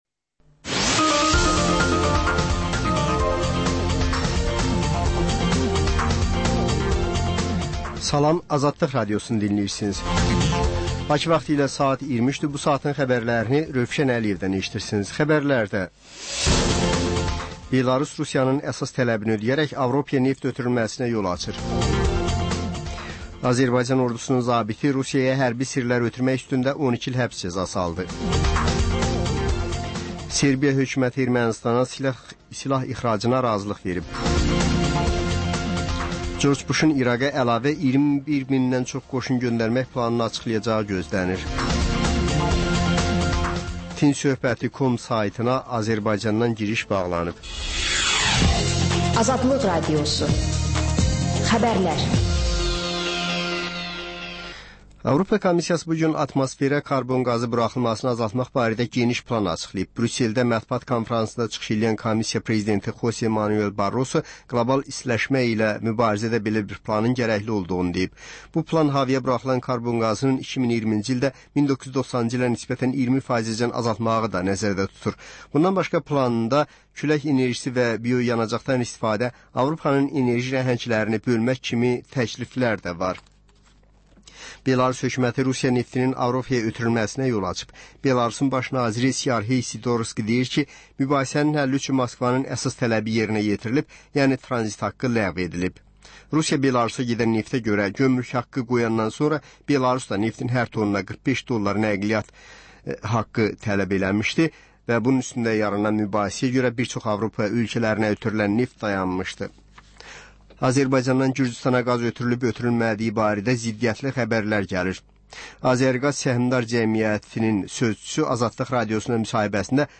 Xəbər, reportaj, müsahibə.